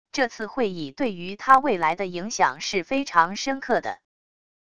这次会议对于他未来的影响是非常深刻的wav音频生成系统WAV Audio Player